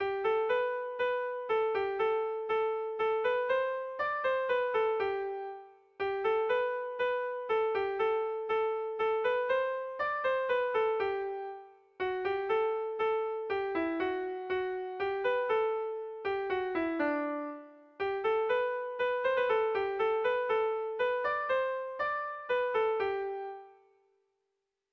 Erlijiozkoa
Zortziko ertaina (hg) / Lau puntuko ertaina (ip)
AABA2